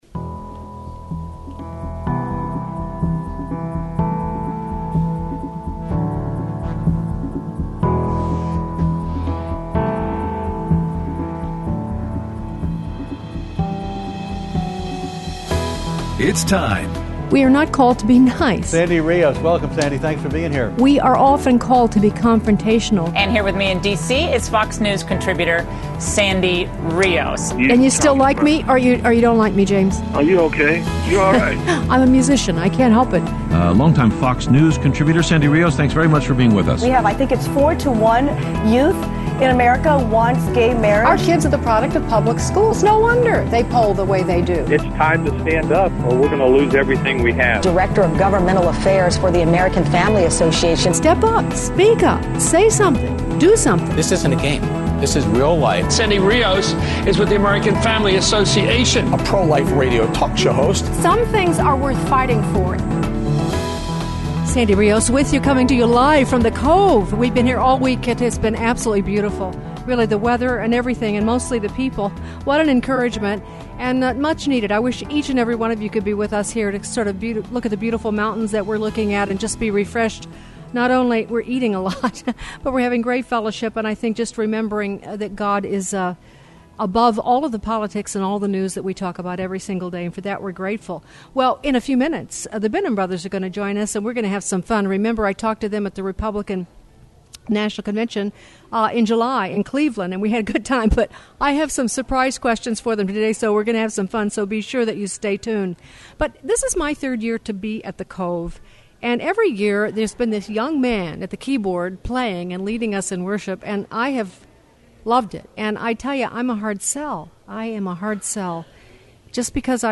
Interviews
at the AFA Retreat at The Cove